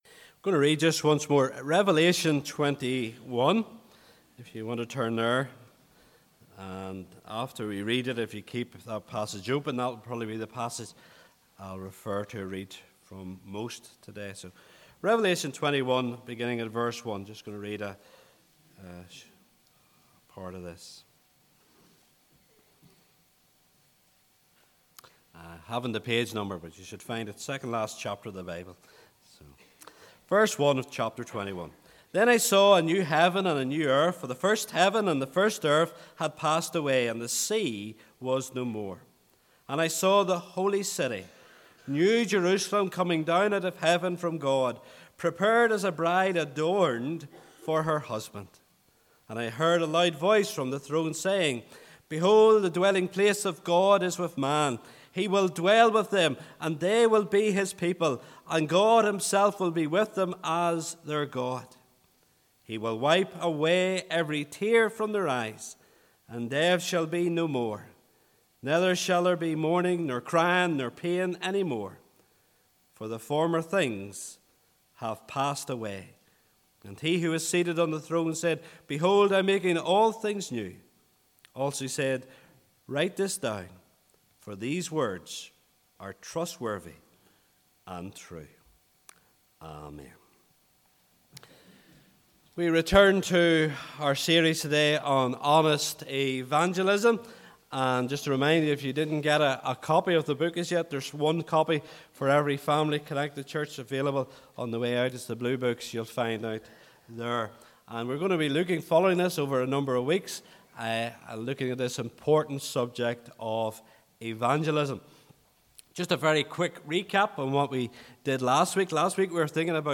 Listen to sermon audio. Revelation 21:1–5 The New Heaven and the New Earth